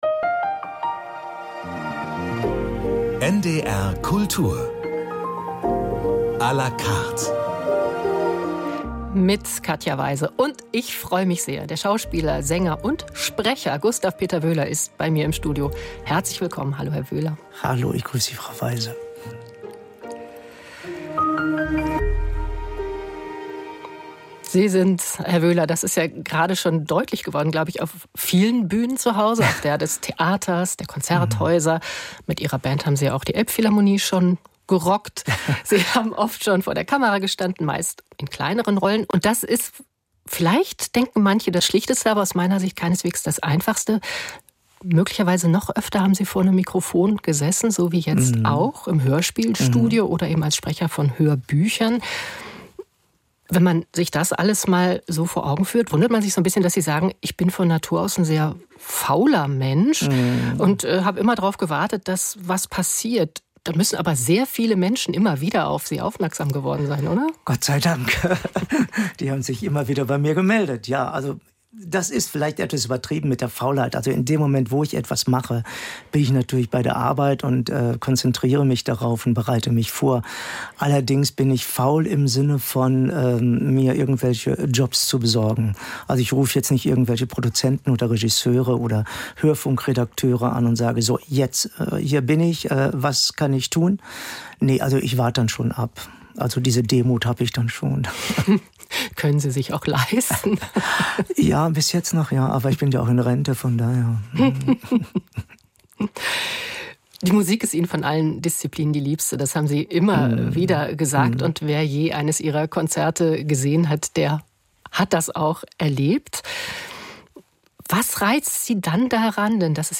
"Früchte des Zorns" - NDR Hörspiel mit Gustav Peter Wöhler - 29.11.2024